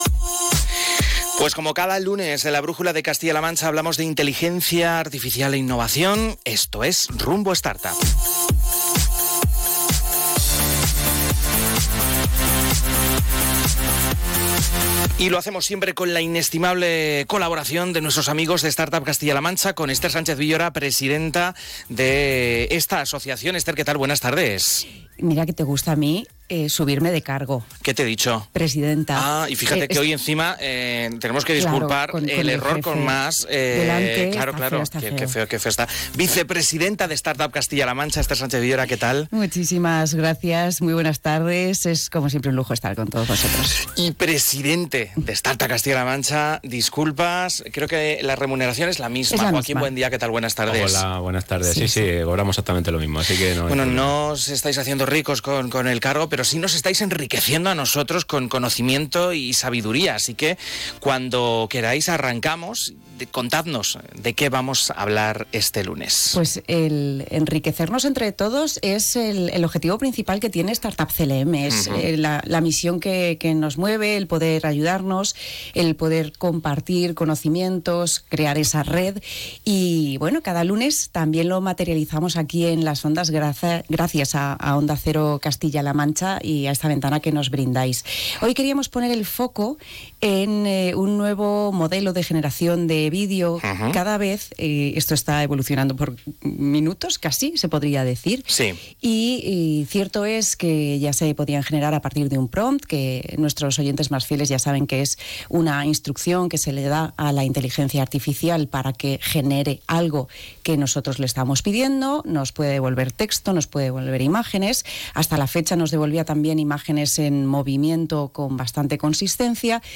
Además, en la tertulia también se habla sin pelos en la lengua de todas las implicaciones: la creatividad se dispara, claro, pero también se hace más complicado distinguir lo auténtico de lo generado.